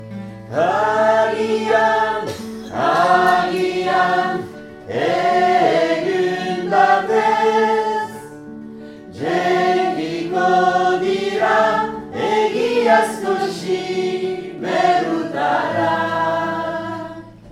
femmes_part2.mp3